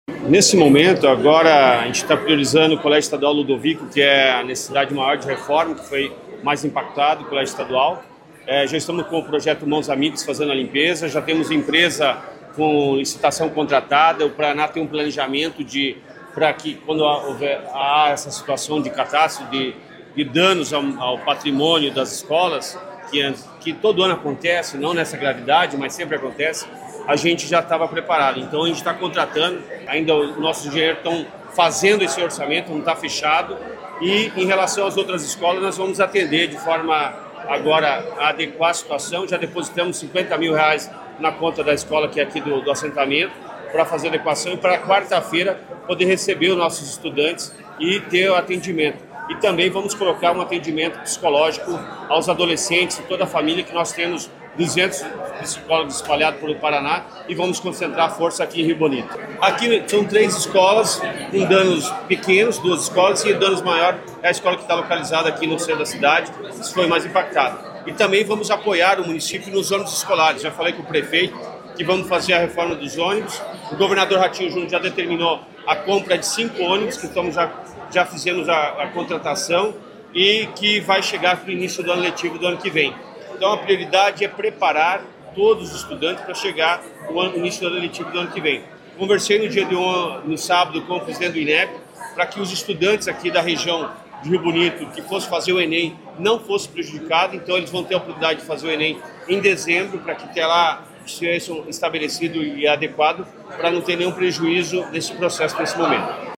Sonora do secretário da Educação, Roni Miranda, sobre as iniciativas de reconstrução para a comunidade de Rio Bonito do Iguaçu